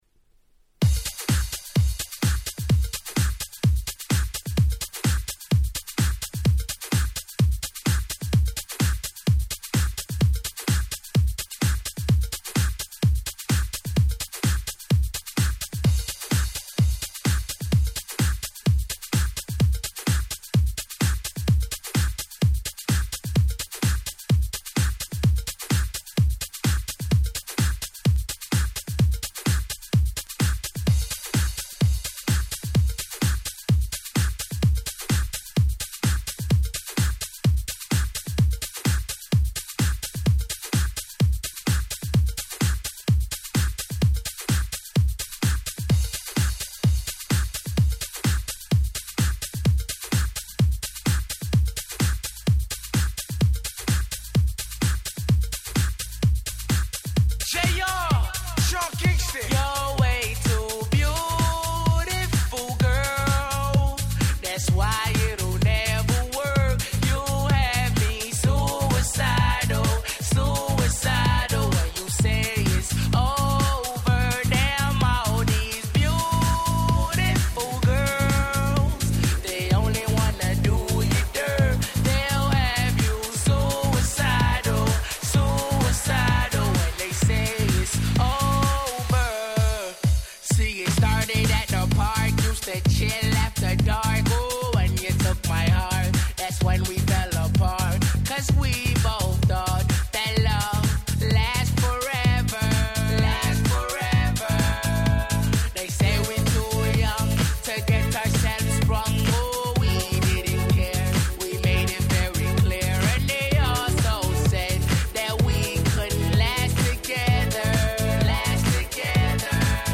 07年のスーパーヒットR&Bを軽快でノリノリなHouse Remixに!!
変にイジり過ぎていないし、よりフロア映えしちゃうしで個人的にもめちゃめちゃ使いました！！